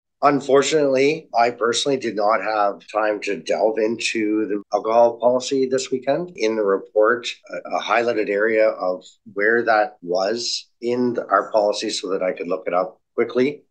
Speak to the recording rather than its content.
Sparks flew in Bluewater council chambers on Monday night (June 18) when councillors discussed the municipality’s alcohol policy.